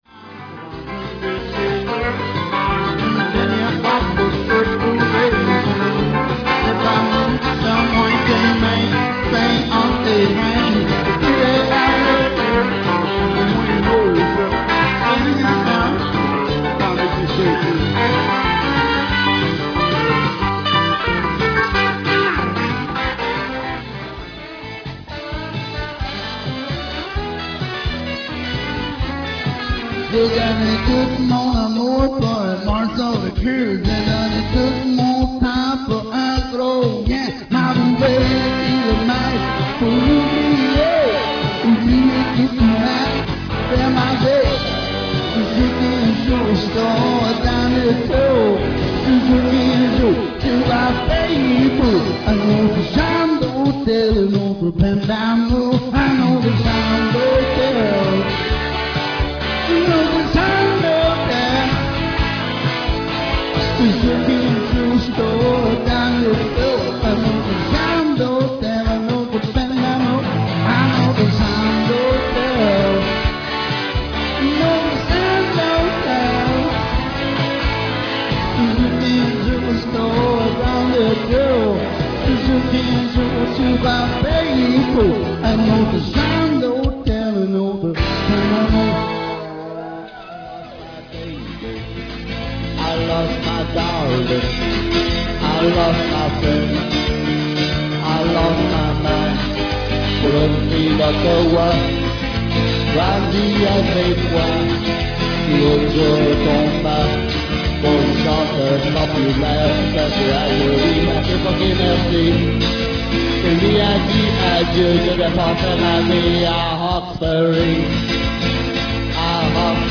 VOICI UN PEU DE CHANSON QUE JE FAIS DANS LES BARS AU QUEBEC